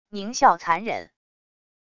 狞笑残忍wav音频